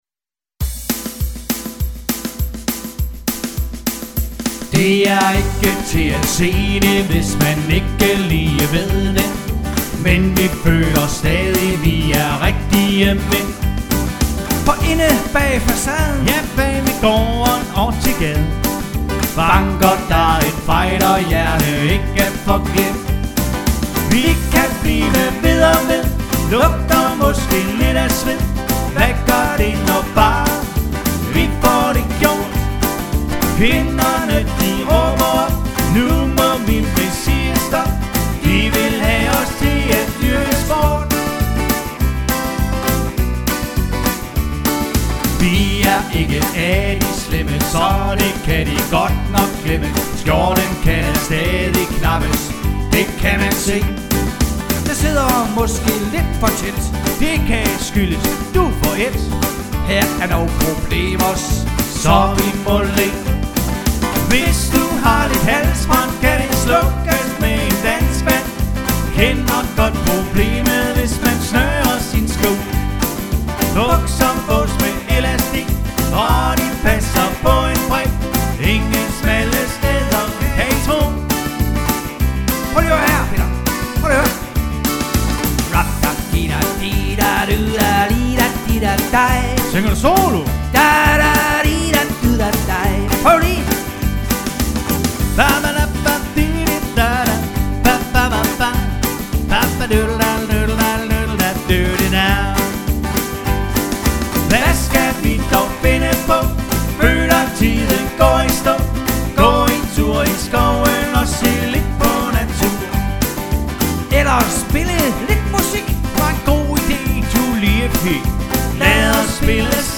Er en duo ,Vi spille til stort set til alle typer af fester.
• Dansk top musik